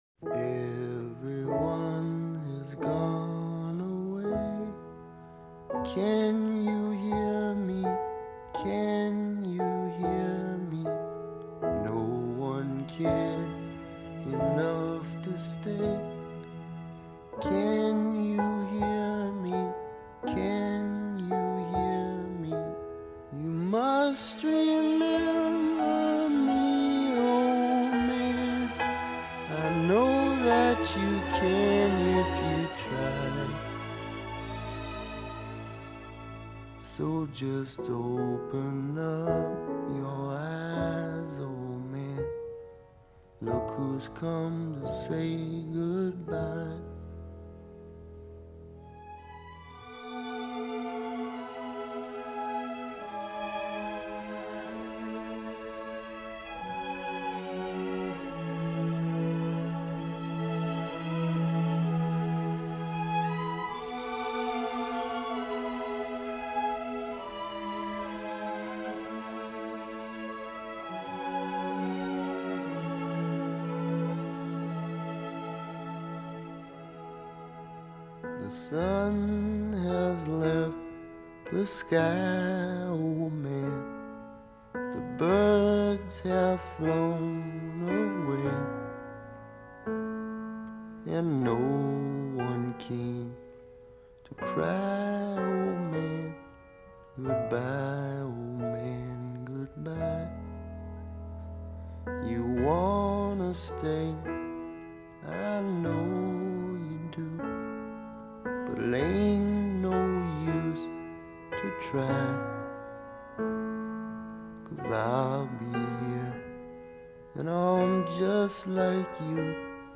the soft, almost searching, music